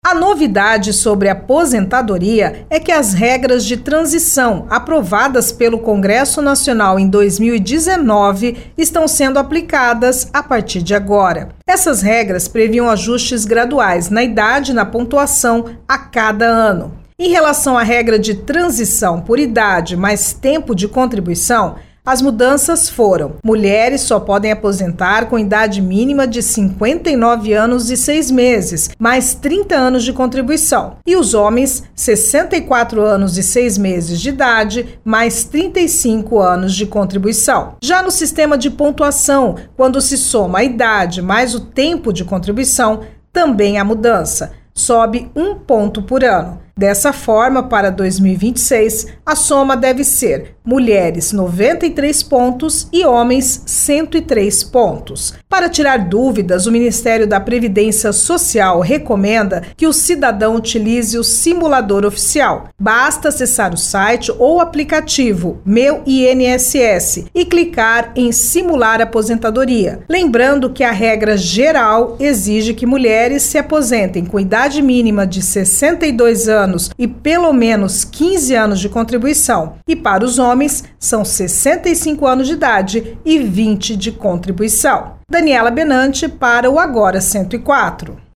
A repórter